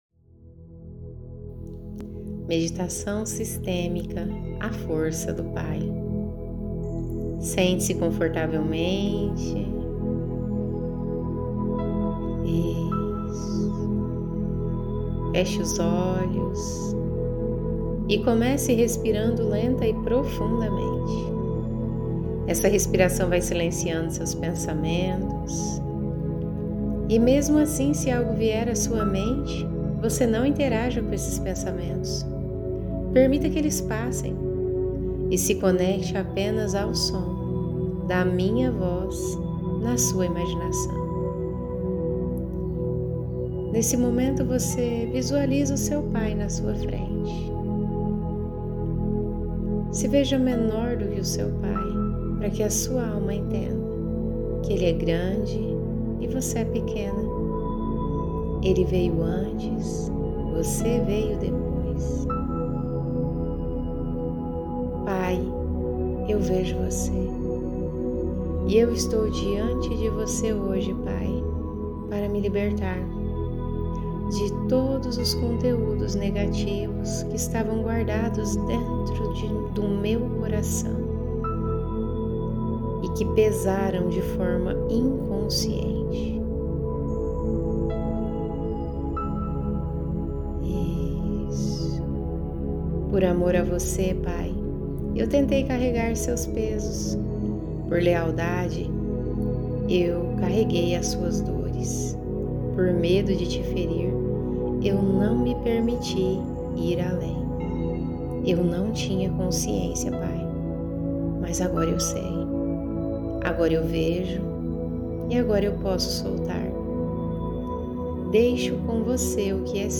Meditação de alinhamento com a força do pai